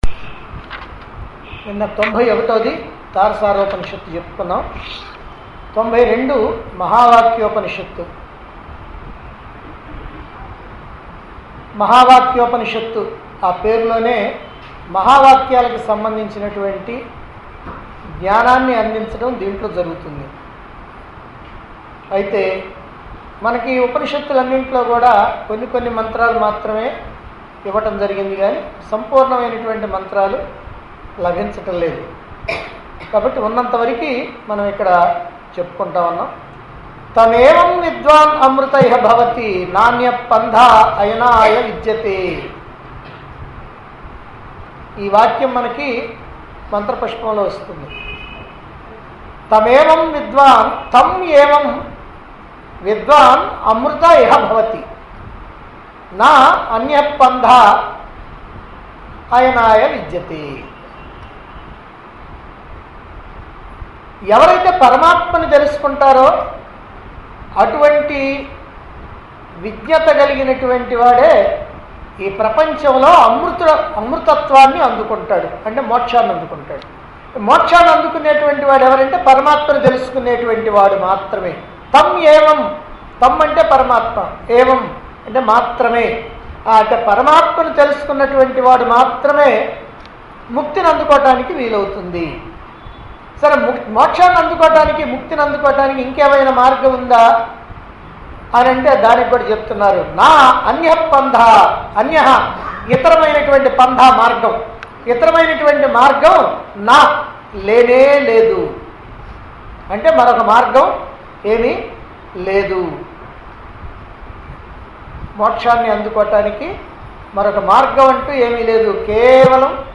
Upanishads - 92.Mahaa Vakya Discourses Recorded On 9-NOV-2011 Discourse Conducted At Chilakaluripet, Guntur Dt. Andhra Pradesh.